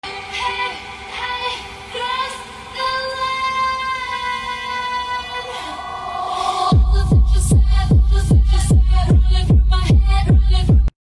Hub sound check of our different hubs with a special something at the end 🐝🔊 Which one do you like the most?